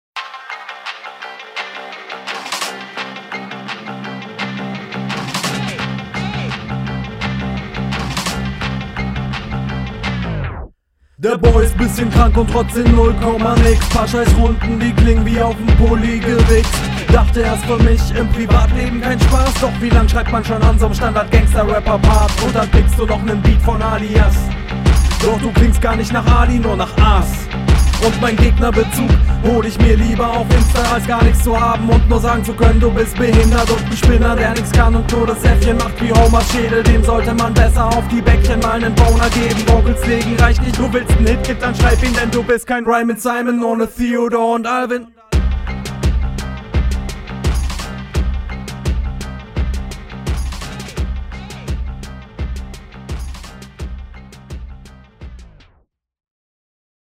Wieder schön hochnäsig und von oben herab.